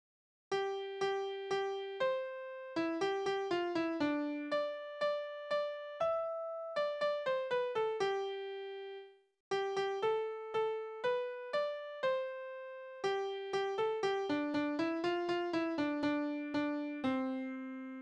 Tonart: C-Dur
Taktart: 4/4
Tonumfang: Dezime